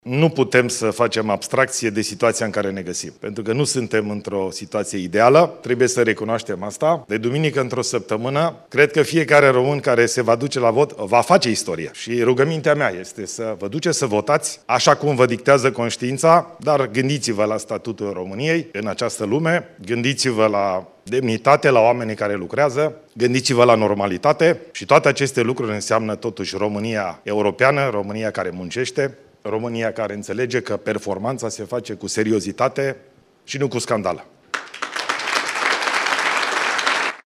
Îndemn la vot din partea președintelui interimar al României. În fața antreprenorilor prezenți la Romanian Business Leaders Summit, Ilie Bolojan a spus miercuri, 8 mai, că îi roagă pe români să meargă la vot, pe 18 mai și să se gândească la statutul României în lume.